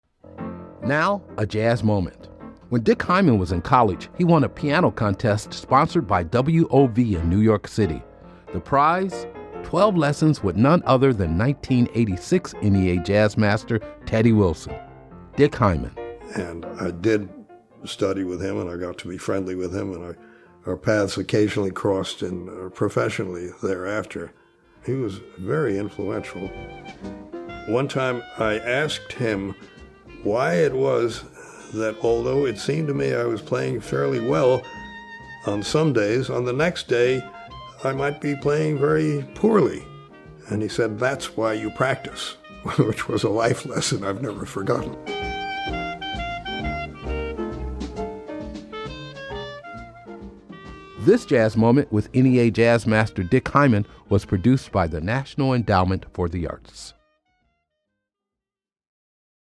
Excerpt of “Beat the Clock Theme” composed and performed by Dick Hyman, from the album Dick Hyman: House of Pianos, uses courtesy of Arbors Music Inc. and by permission of Leosol Enterprises c/o Universal Music [BMI]. Excerpt of “On the Sunny Side of the Street” composed by Jimmy McHugh and performed by Teddy Wilson, from the album, Teddy Wilson, used courtesy LRC Ltd. and used by permission of Cotton Club Publishing Sony/ATV.